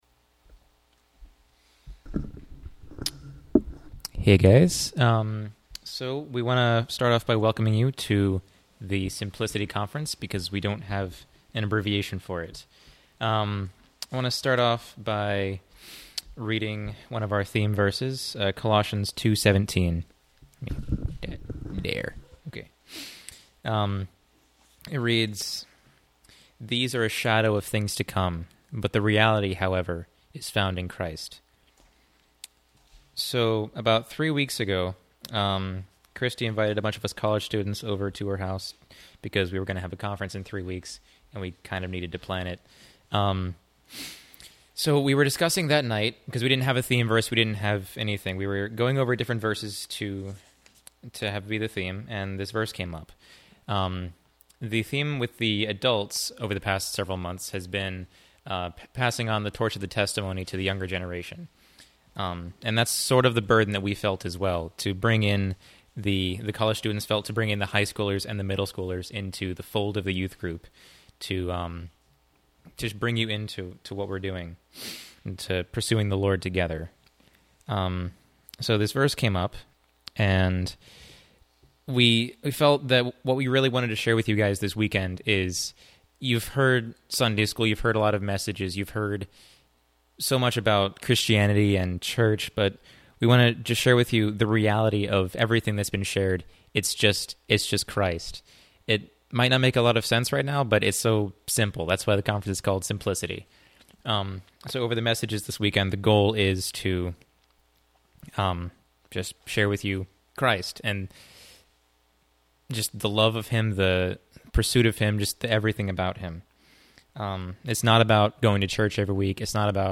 Simplicity Winter Youth Conference